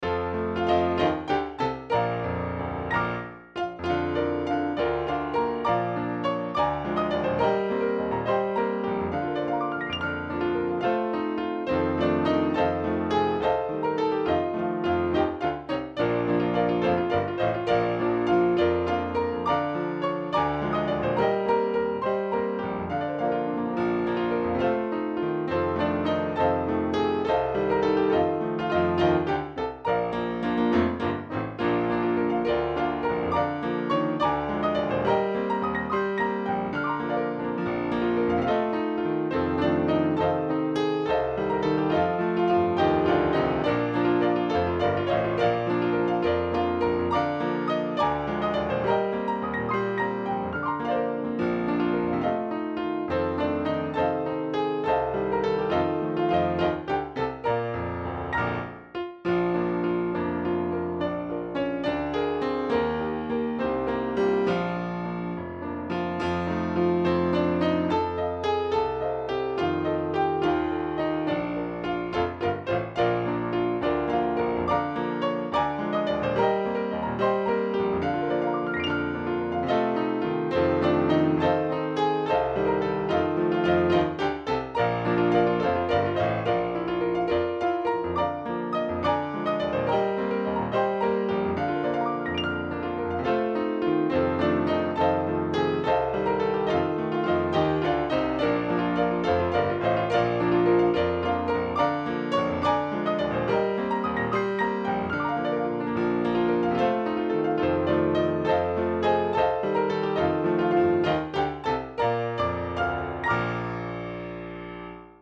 Key: B♭